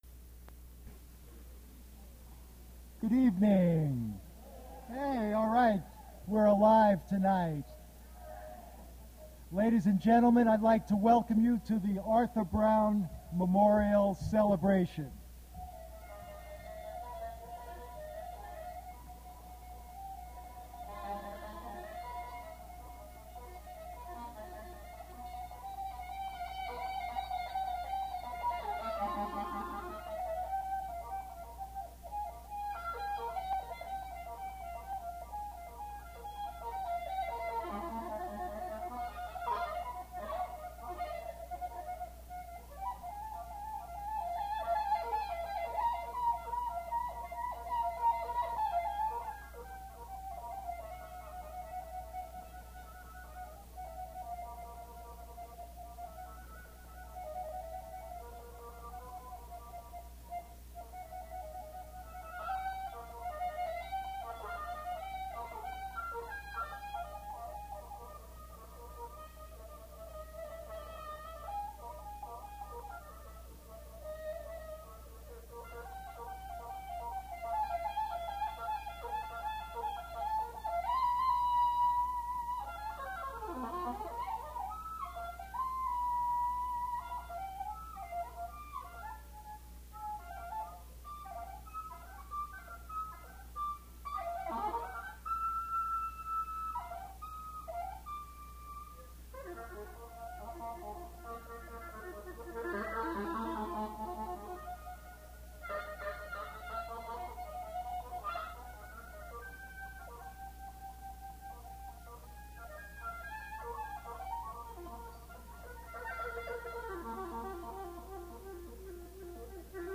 Poetry reading featuring Maurice Kenny
Attributes Attribute Name Values Description Maurice Kenny poetry reading at Duff's Restaurant.
mp3 edited access file was created from unedited access file which was sourced from preservation WAV file that was generated from original audio cassette.